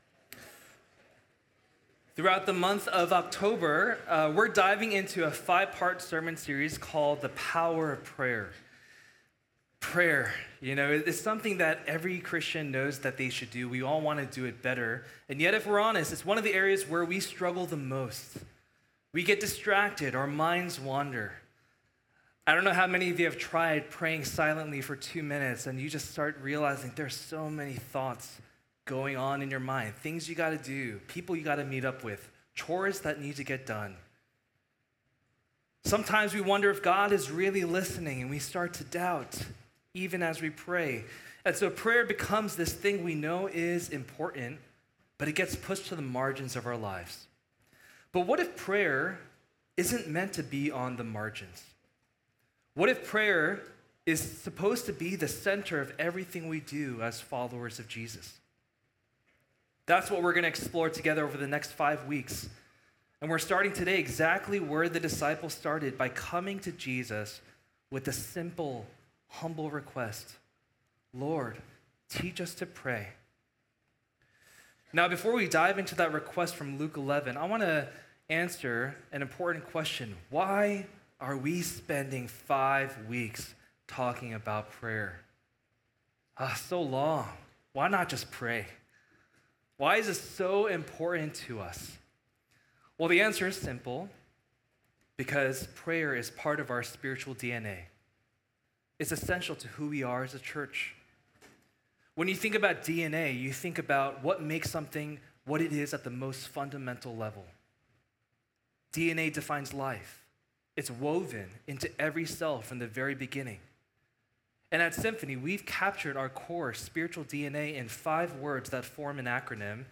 Sermons | Symphony Church